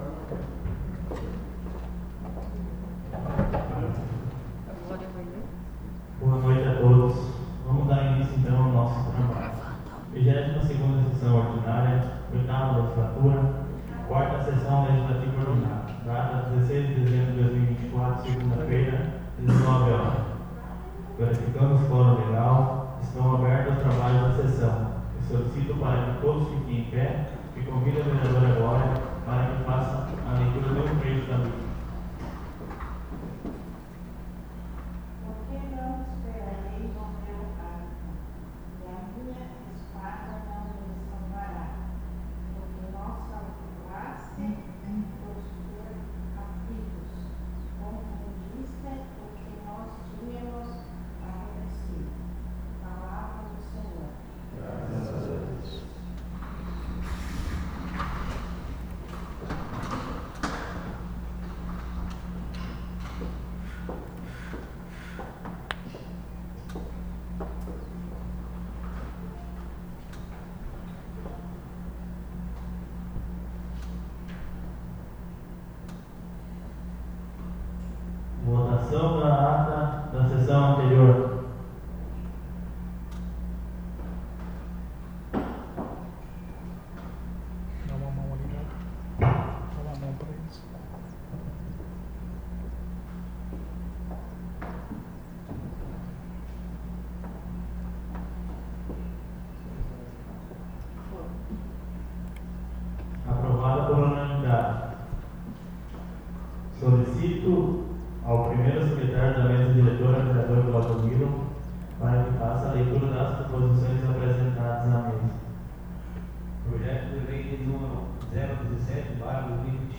Audio da 22ª Sessão Ordinária 16-12-24